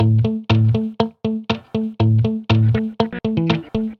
Tag: 120 bpm Funk Loops Guitar Acoustic Loops 689.24 KB wav Key : Unknown